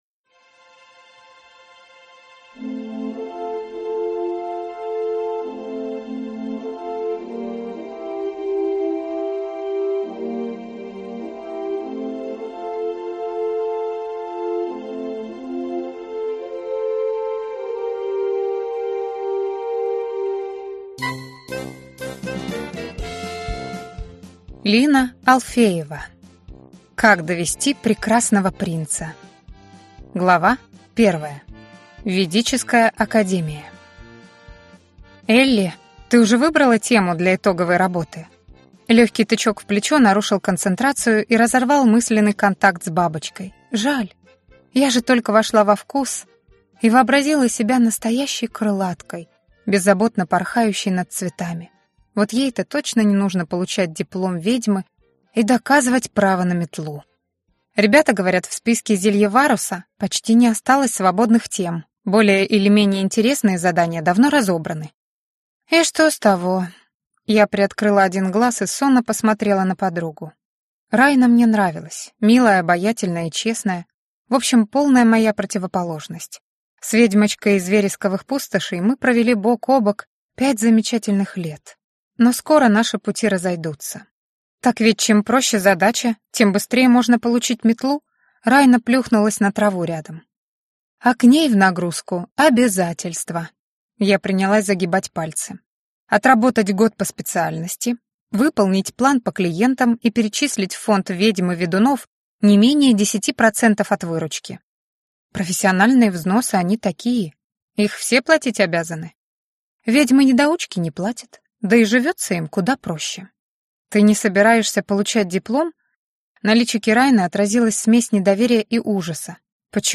Аудиокнига Как довести прекрасного принца | Библиотека аудиокниг